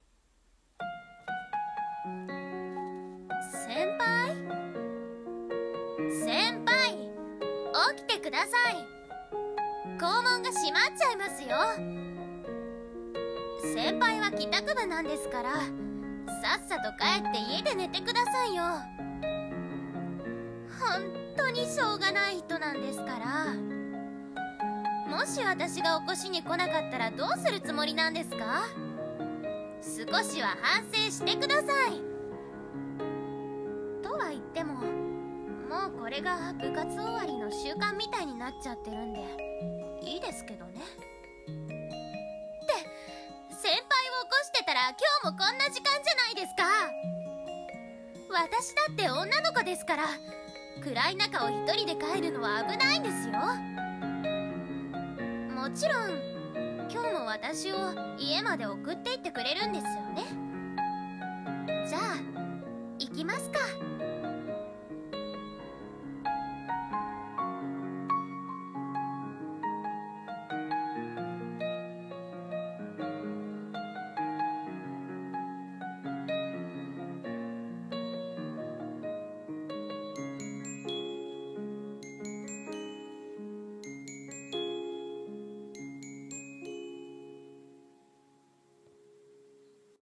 【一人声劇】かわいい後輩 【女声向け】